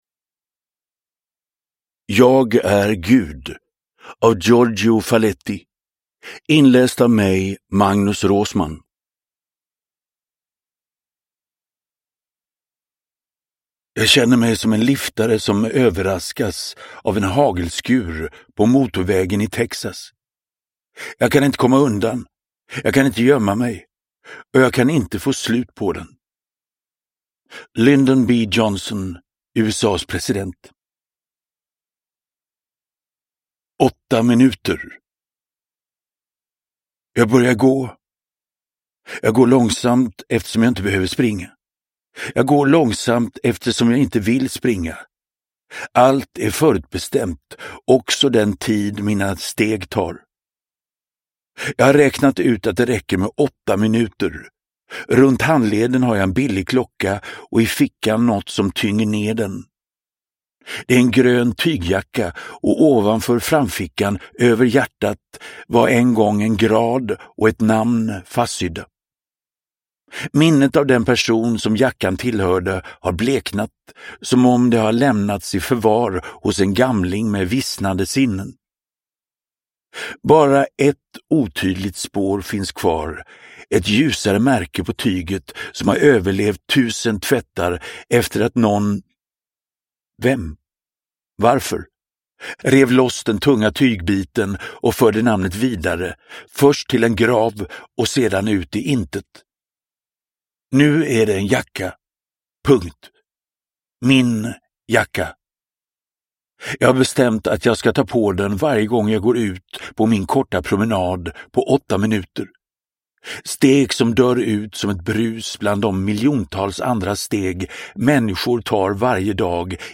Jag är Gud – Ljudbok – Laddas ner
Uppläsare: Magnus Roosmann